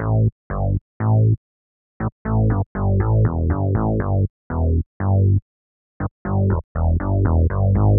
29 Bass PT3.wav